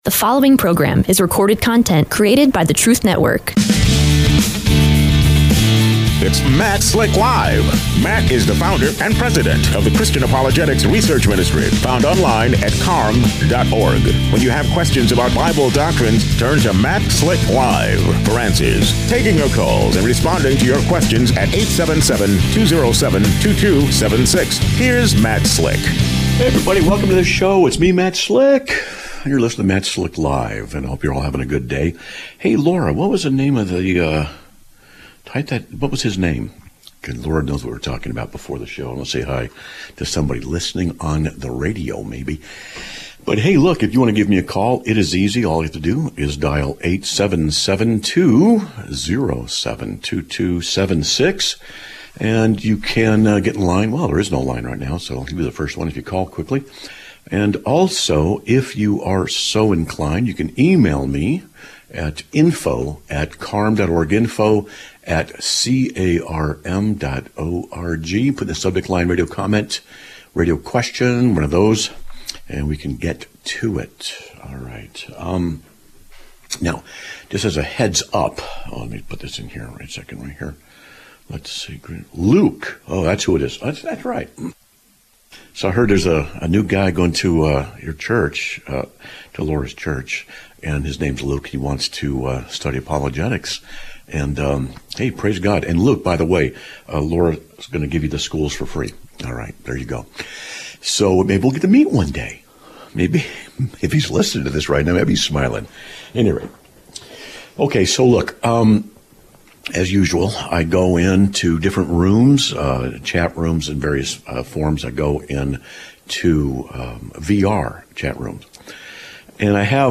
Live Broadcast of 02/02/2026
A Caller Discusses his Witnessing Experience with Jehovah's Witnesses